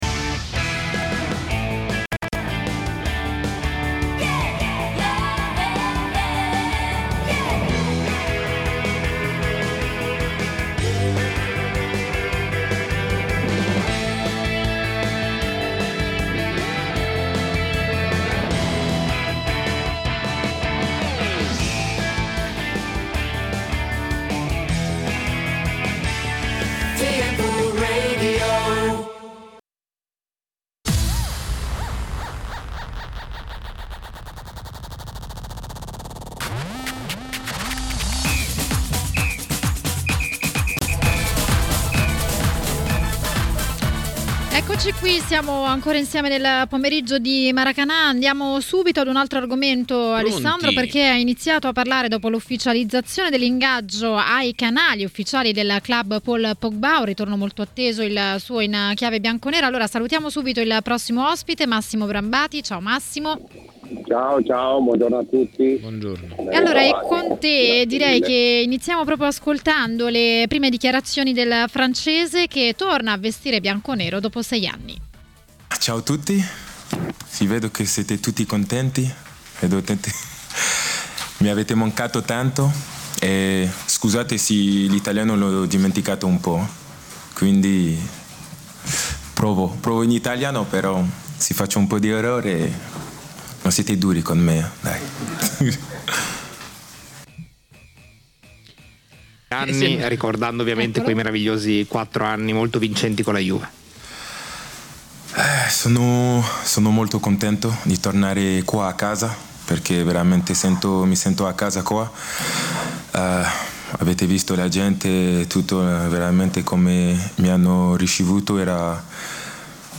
A Maracanà, nel pomeriggio di TMW Radio, è arrivato il momento di Antonio Di Gennaro, ex calciatore e commentatore tv.